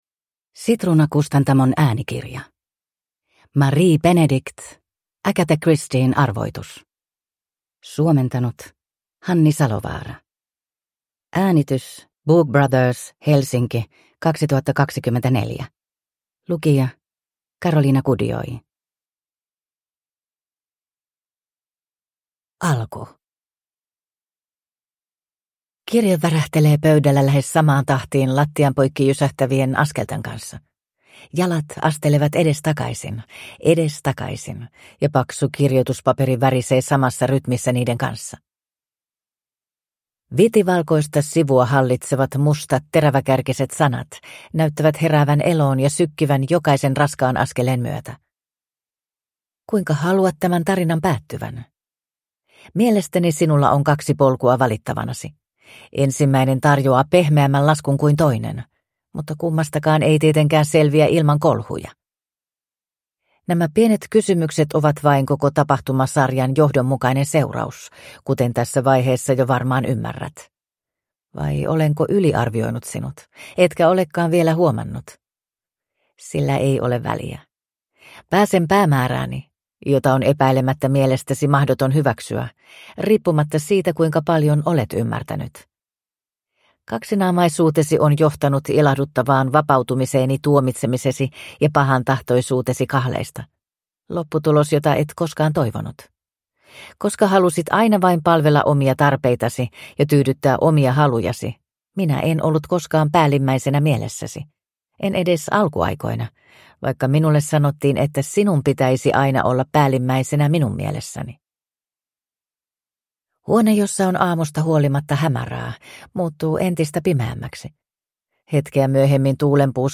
Agatha Christien arvoitus – Ljudbok
• Ljudbok